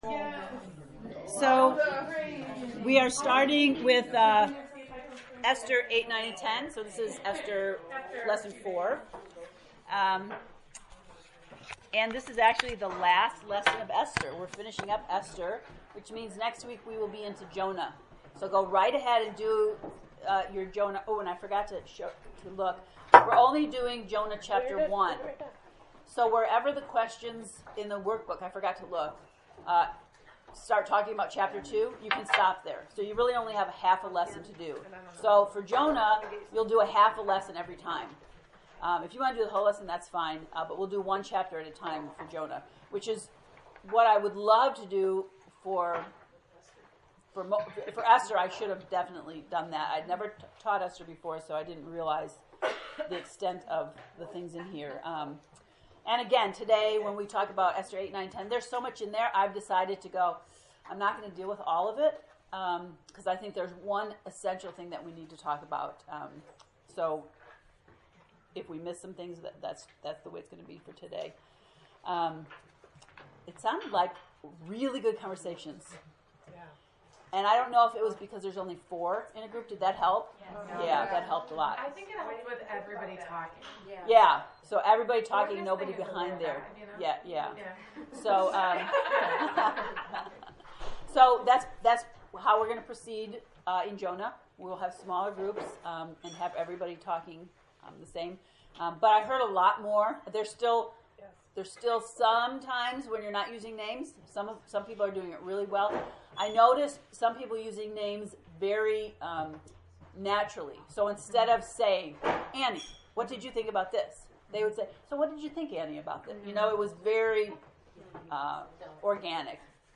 ESTHER lesson 4